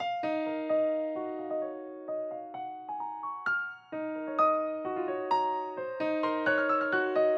Tag: 130 bpm Trap Loops Piano Loops 1.24 MB wav Key : D Cubase